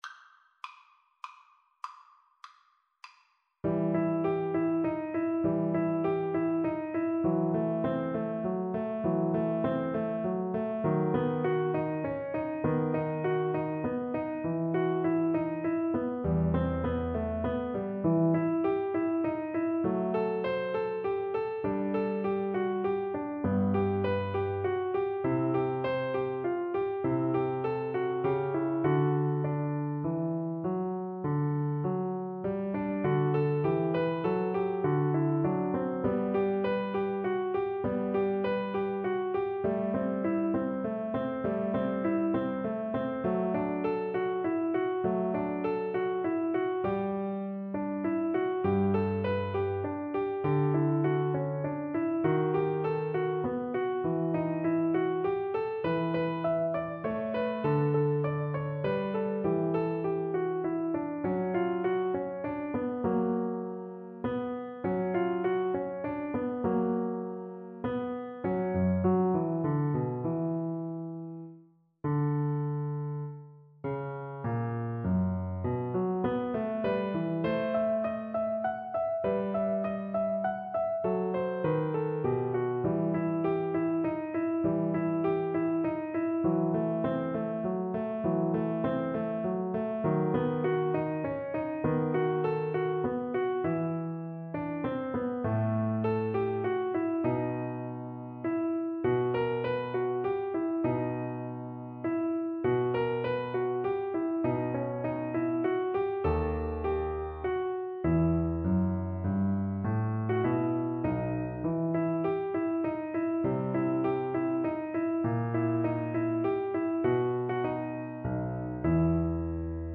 Siciliano =100
Classical (View more Classical Violin Music)